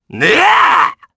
Ezekiel-Vox_Attack2_jp.wav